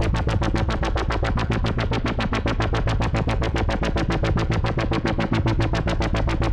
Index of /musicradar/dystopian-drone-samples/Tempo Loops/110bpm
DD_TempoDroneA_110-A.wav